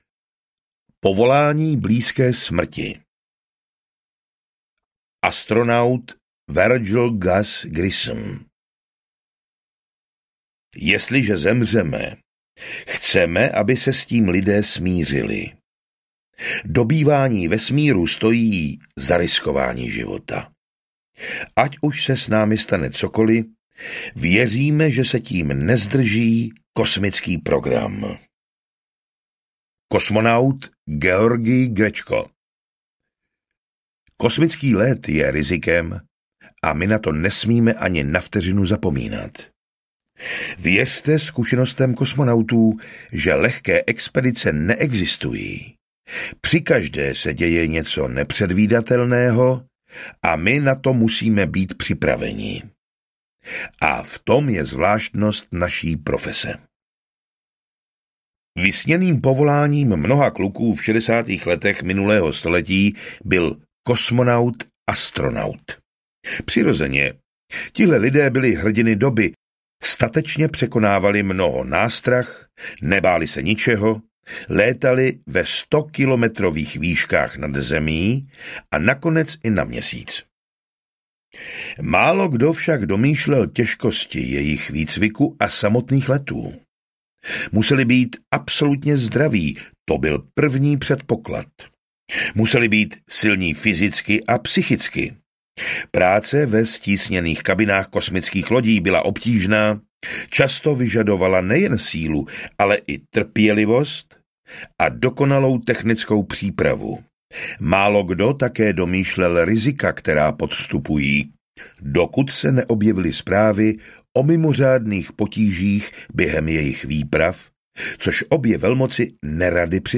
Kosmonauti na pokraji smrti audiokniha
Ukázka z knihy